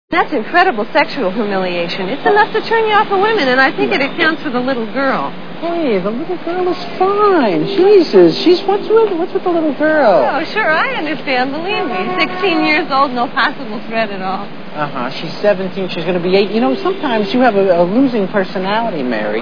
Manhattan Movie Sound Bites